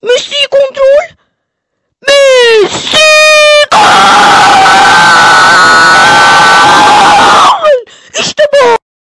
mesii Meme Sound Effect